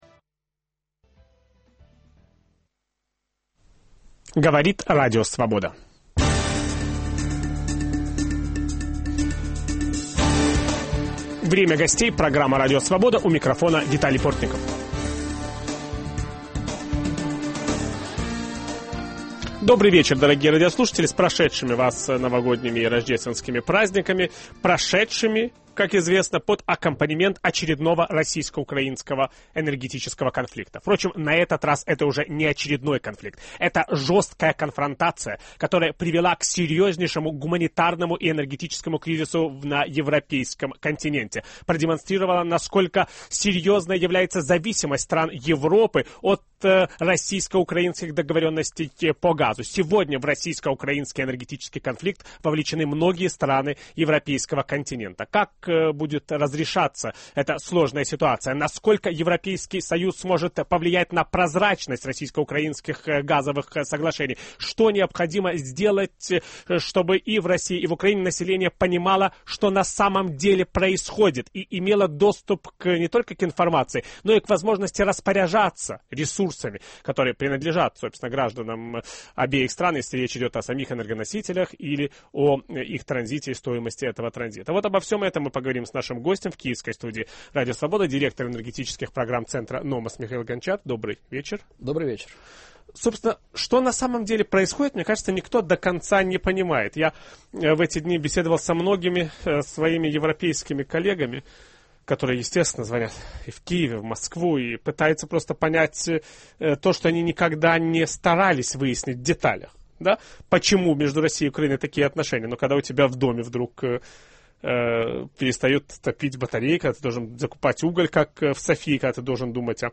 О российско-украинском газовом конфликте ведущий программы Виталий Портников беседует